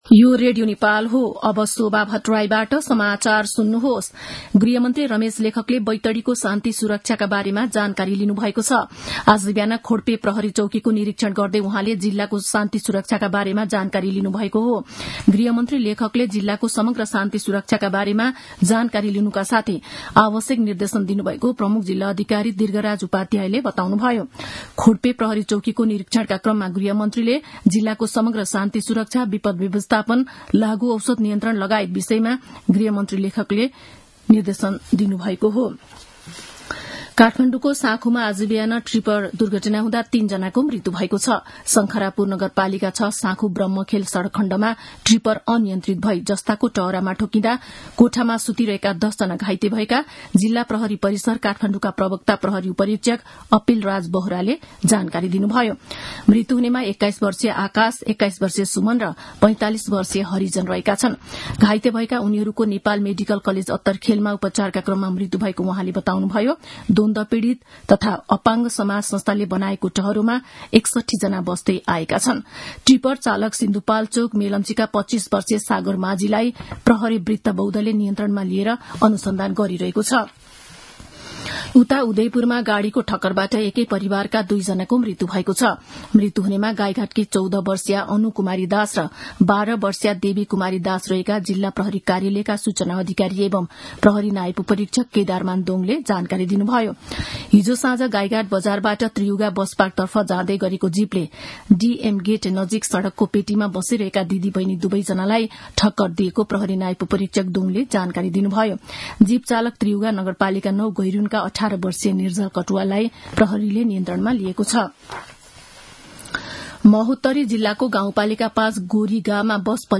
दिउँसो १ बजेको नेपाली समाचार : २४ फागुन , २०८१